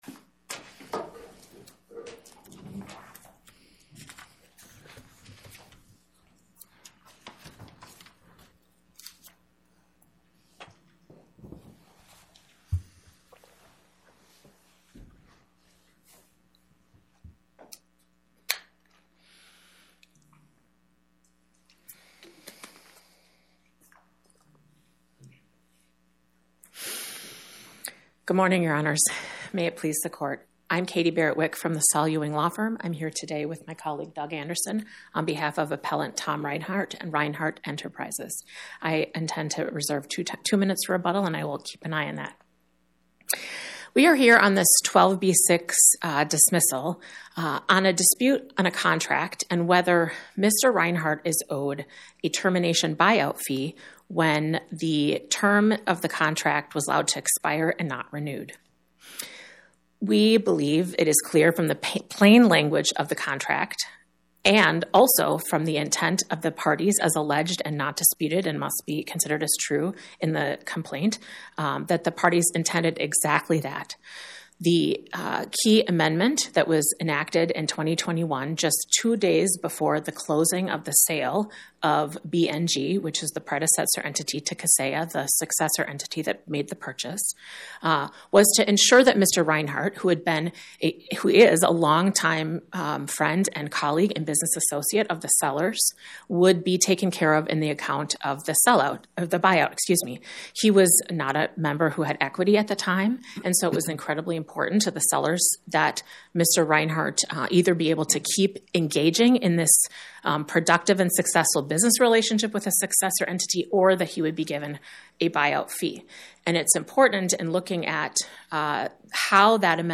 My Sentiment & Notes 25-1069: Reinhardt Enterprises, LLC vs Kaseya U.S., LLC Podcast: Oral Arguments from the Eighth Circuit U.S. Court of Appeals Published On: Thu Oct 23 2025 Description: Oral argument argued before the Eighth Circuit U.S. Court of Appeals on or about 10/23/2025